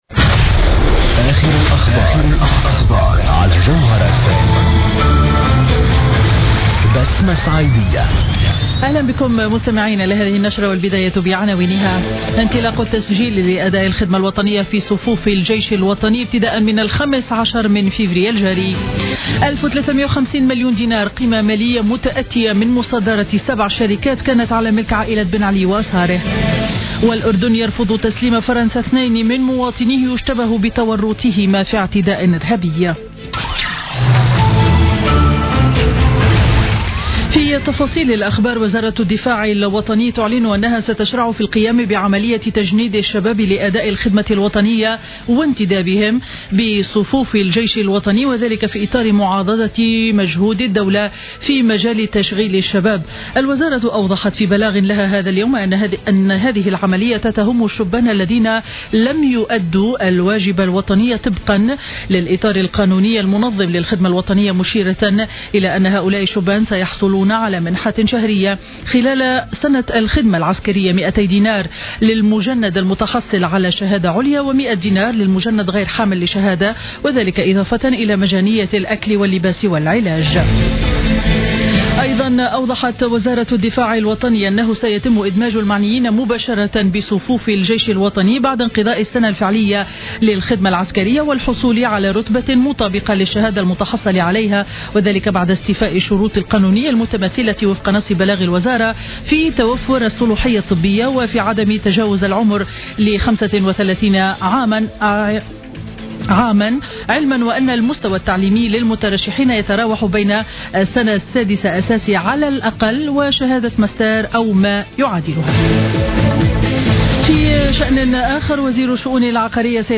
Journal Info 12h00 du mercredi 10 février 2016